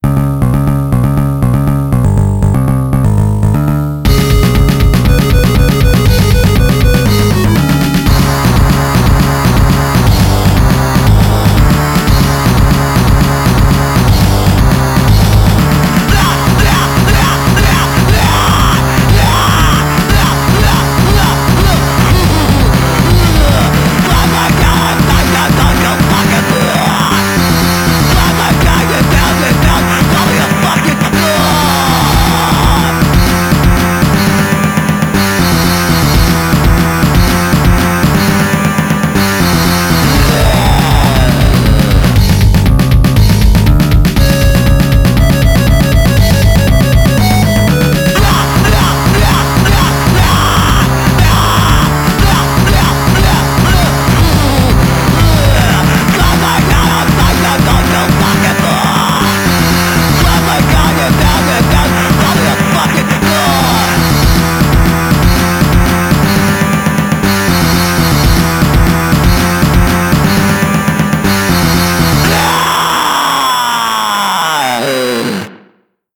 punk, digital hardcore, hardcore, grindcore, vgm, ,